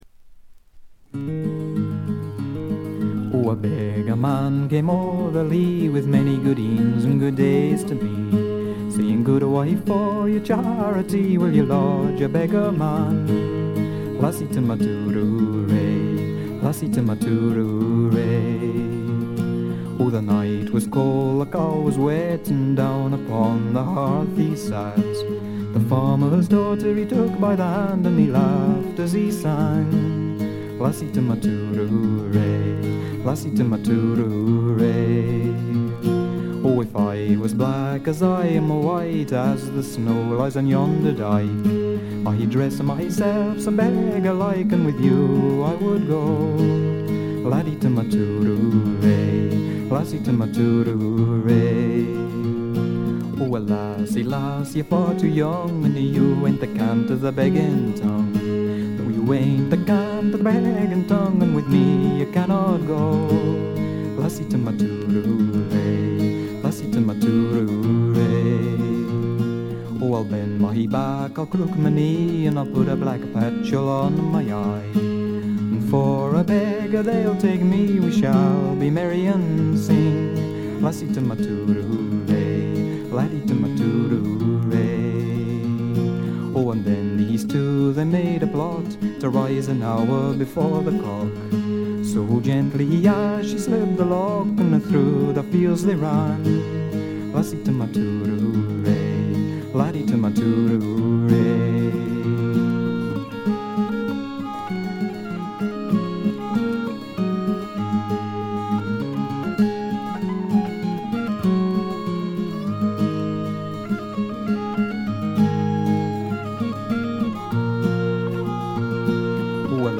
微細なチリプチわずか。
自身のギター、ダルシマー、ツィターの他はコンサーティナ兼笛の奏者が付くだけの極めてシンプルな演奏を聴かせます。
まるで静寂そのものを聴かせるような、静謐で至上の美しさをたたえた作品です。
試聴曲は現品からの取り込み音源です。
Melodeon, Whistle, Concertina
Recorded At - Tonstudio St. Blasien